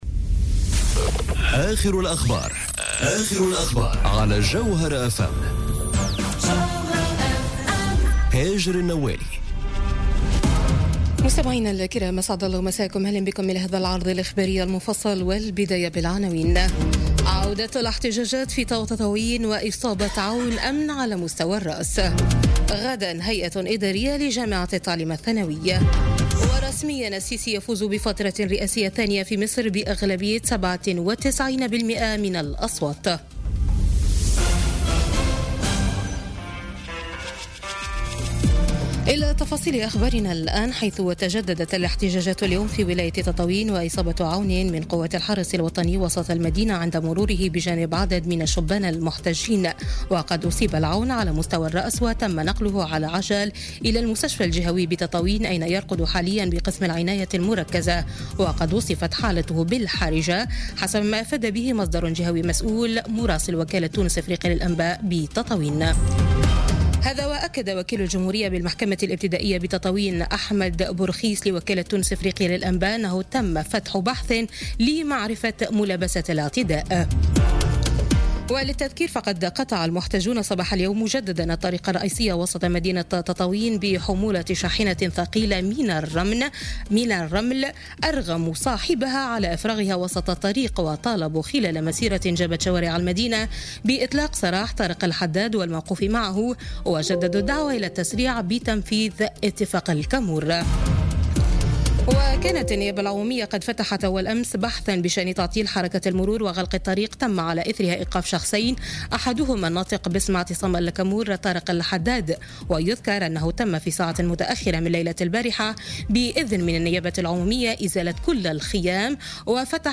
نشرة أخبار السابعة مساء ليوم الاثنين 2 أفريل 2018